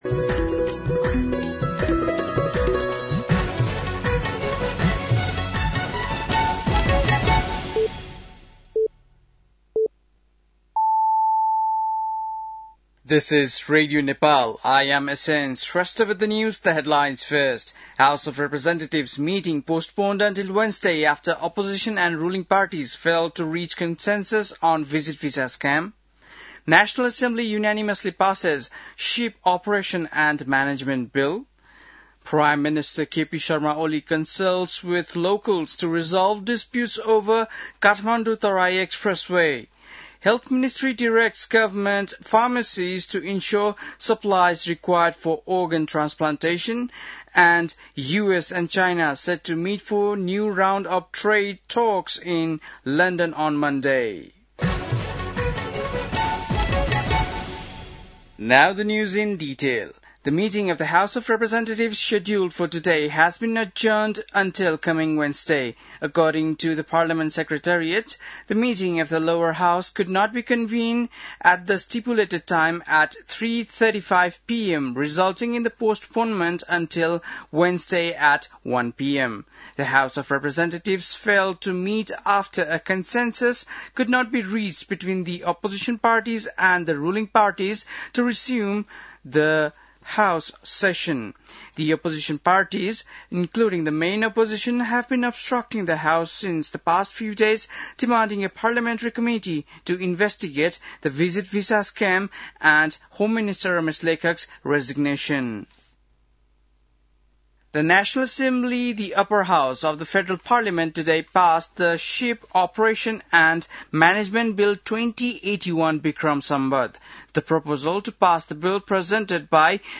बेलुकी ८ बजेको अङ्ग्रेजी समाचार : २६ जेठ , २०८२
8-PM-English-NEWS-1-1.mp3